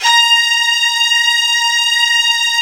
55u-va13-A#4.aif